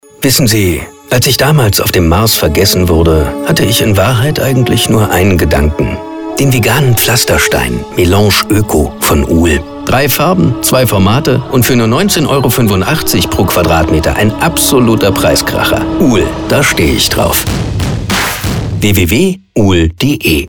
Funkspot